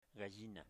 Phonological Representation ga'ʒina